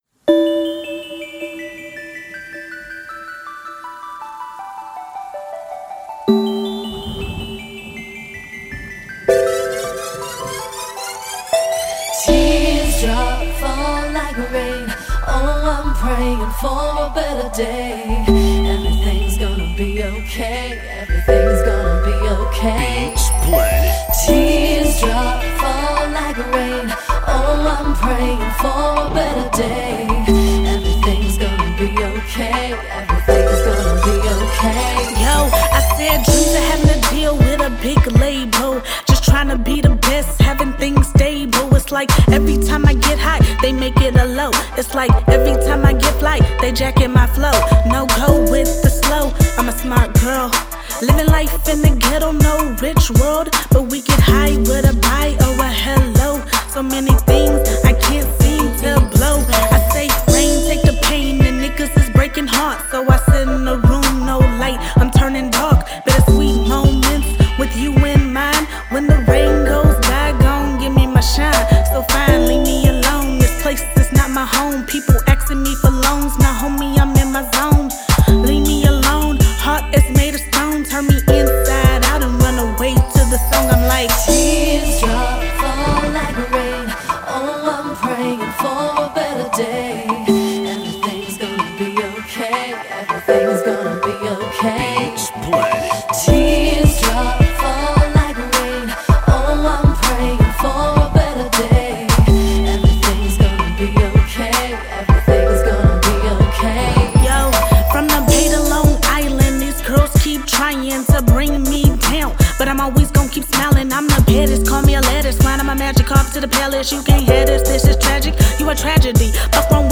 I love how you sound very passionate.
Also, as I was listening, I heard mostly your strength, and how confident you sound.
You sound very young and I’m very surprised about how deep you took this song.
Your voice sounds so good.